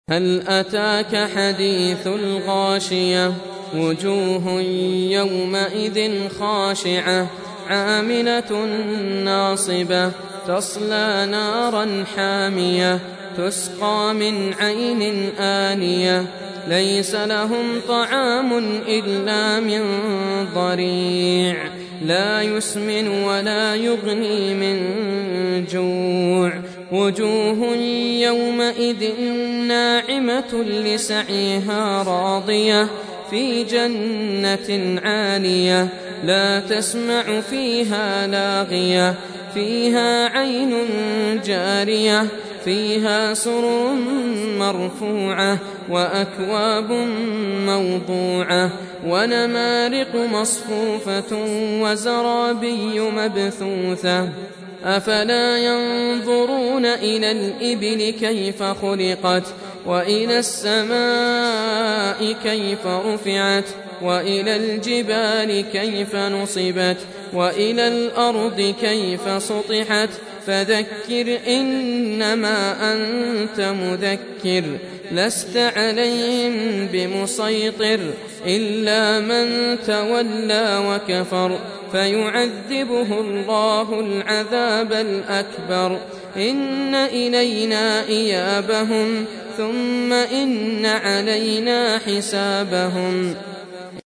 Surah Repeating تكرار السورة Download Surah حمّل السورة Reciting Murattalah Audio for 88.